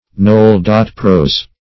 Nol-pros \Nol`-pros"\, v. t. [imp. & p. p. -prossed; p. pr. &